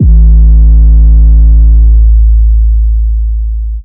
808 6 {A} [ Drone ].wav